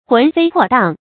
魂飛魄蕩 注音： ㄏㄨㄣˊ ㄈㄟ ㄆㄛˋ ㄉㄤˋ 讀音讀法： 意思解釋： 同「魂飛魄散」。